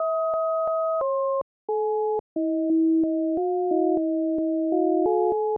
tone.wav